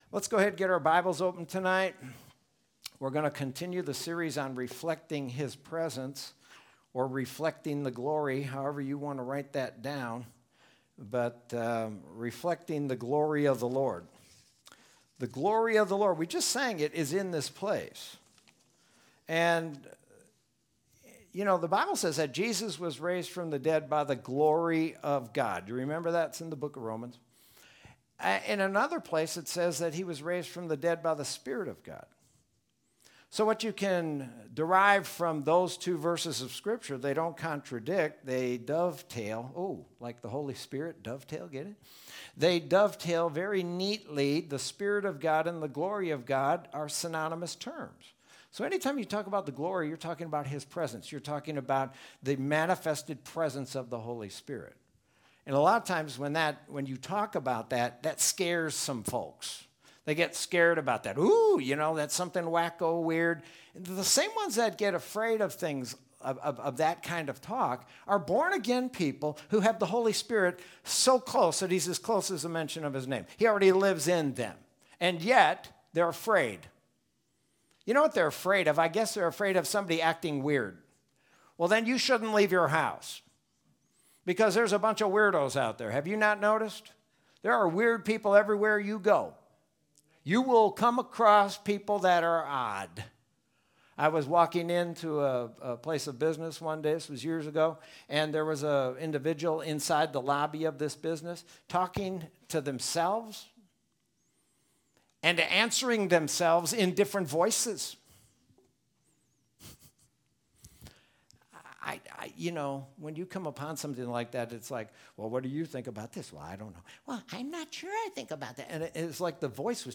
Sermon from Wednesday, August 19th, 2020.